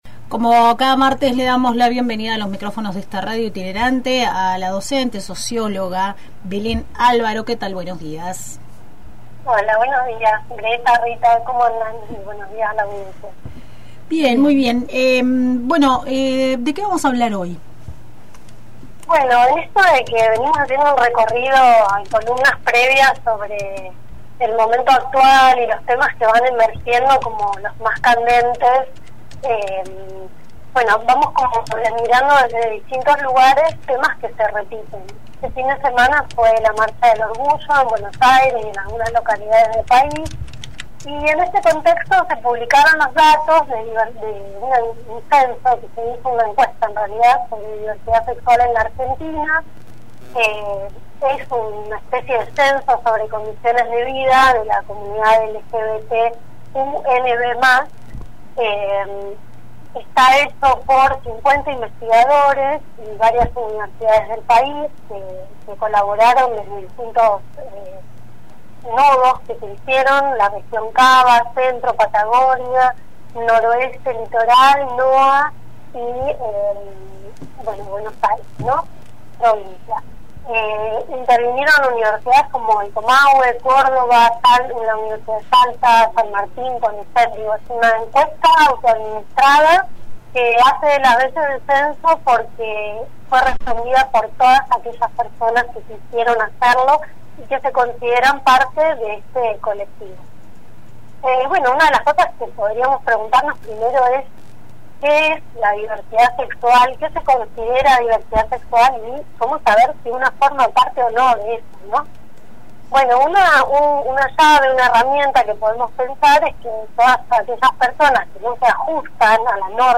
En diálogo con Radio Itinerante